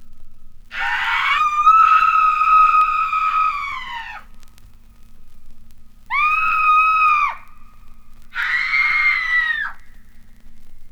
• three screams - female.wav
Recorded from Sound Effects - Death and Horror rare BBC records and tapes vinyl, vol. 13, 1977. Equipment used: TTA05USB akai player and focusrite 8i8 interface, using an SSL limiter for some dimmed s...
three_screams_-_female_1Cq.wav